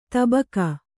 ♪ tabaka